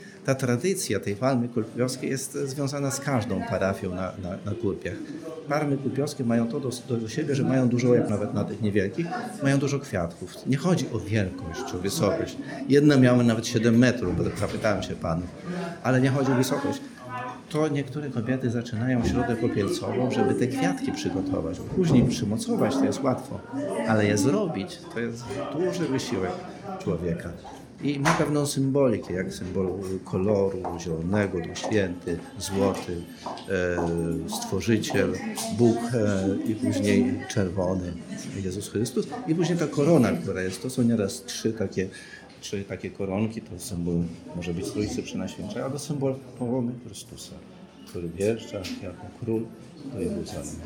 O tradycji palmy kurpiowskiej mówi bp Stepnowski.